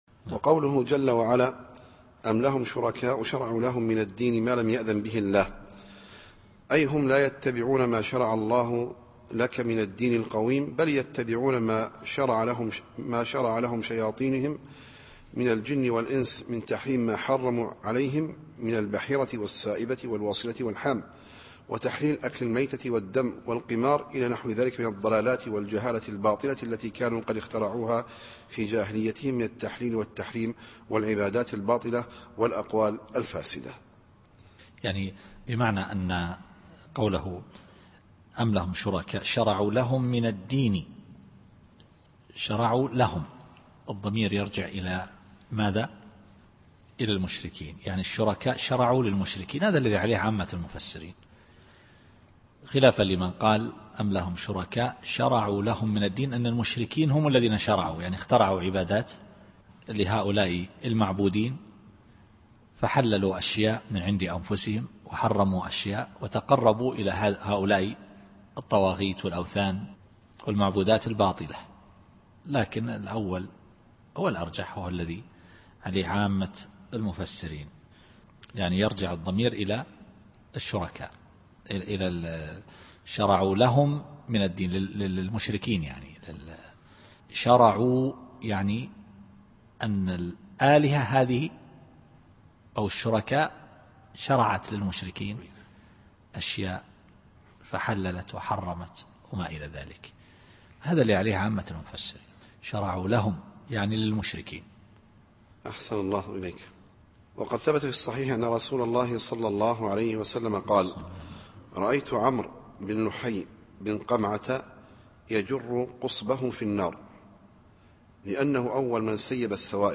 التفسير الصوتي [الشورى / 21]